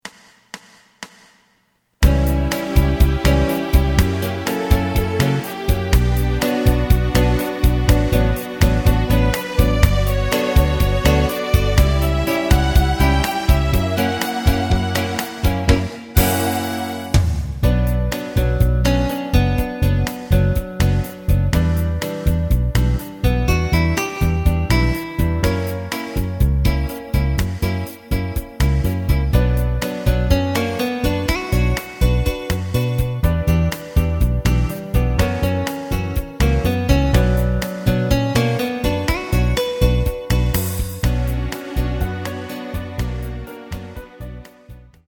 フルコーラス(カラオケ)